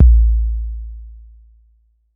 Tr8 Kick 04.wav